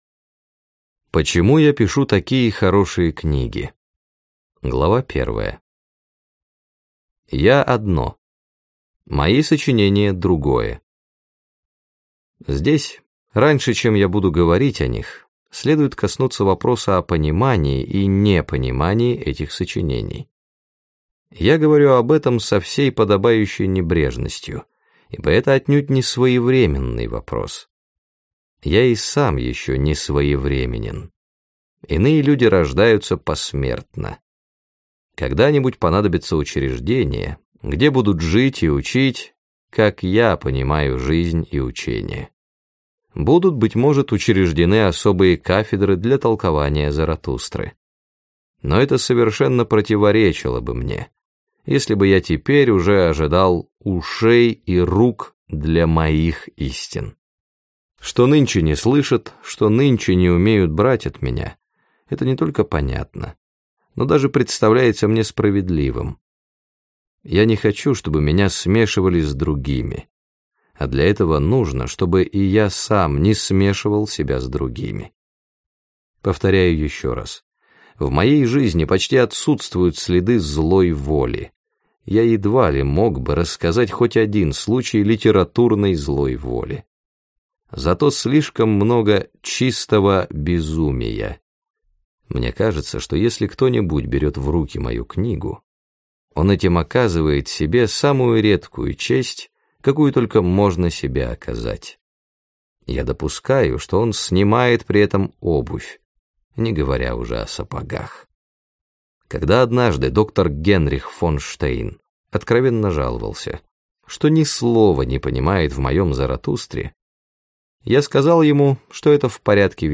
Аудиокнига Ecce Homo. Как становятся сами собою | Библиотека аудиокниг